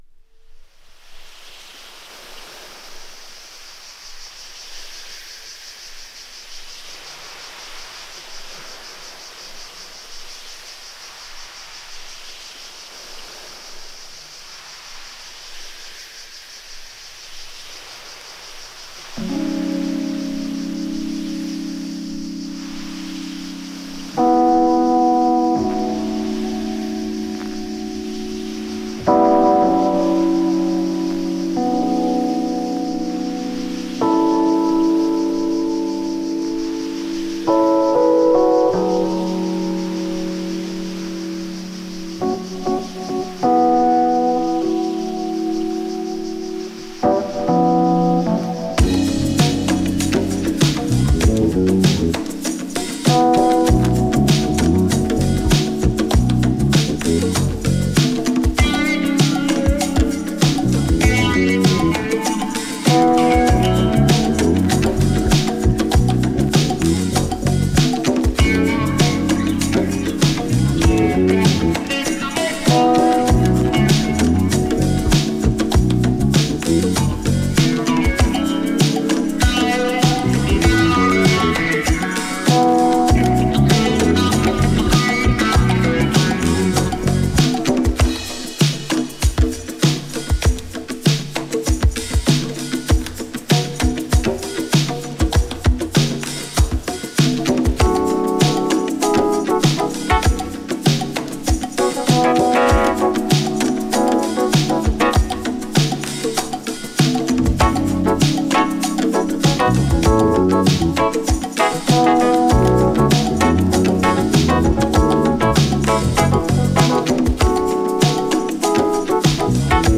生楽器をフィーチャーした70年代スムースジャズ・テイスト!